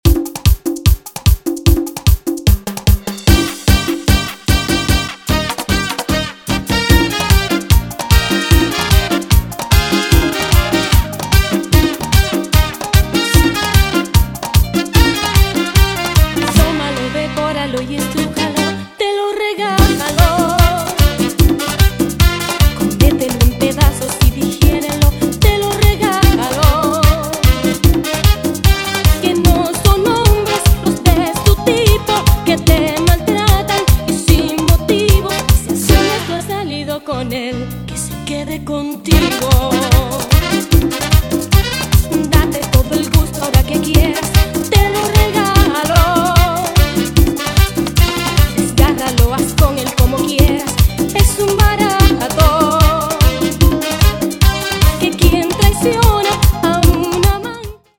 mezcla la esencia latina con ritmos modernos
guaracha, salsa remix, cumbia remix, EDM latino